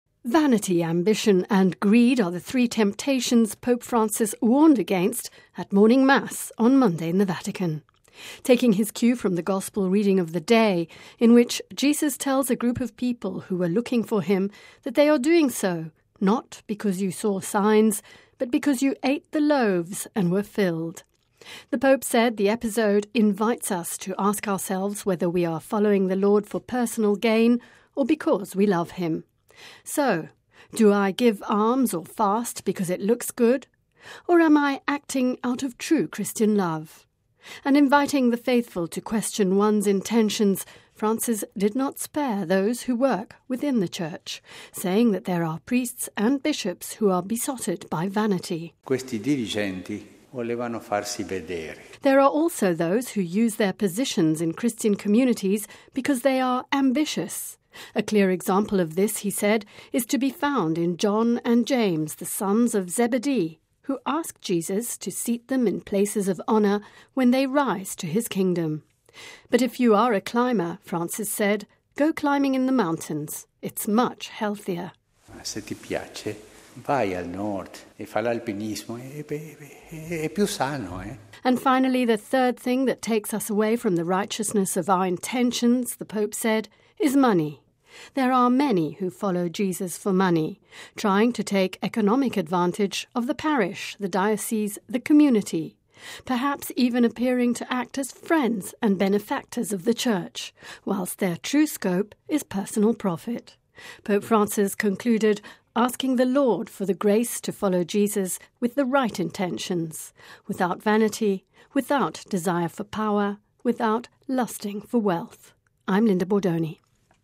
The Pope was speaking during morning Mass at Casa Santa Marta.